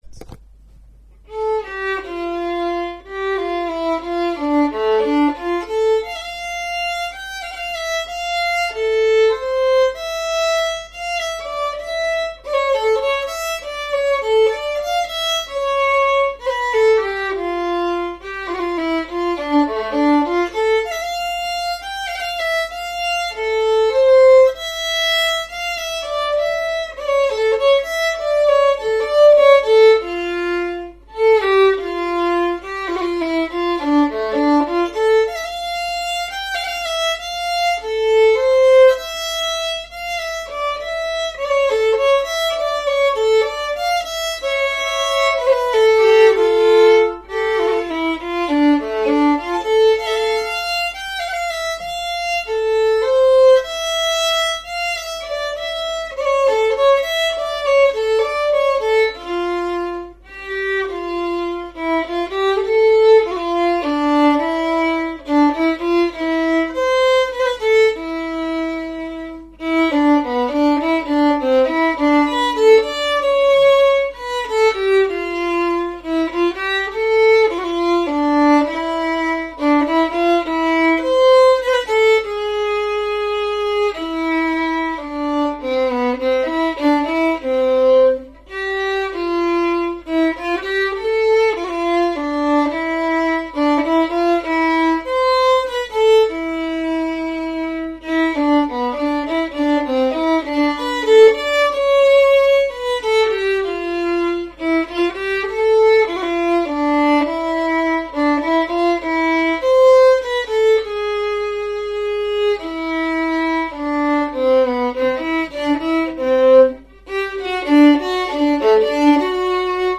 Vals
Mariannas_vals_stämma.mp3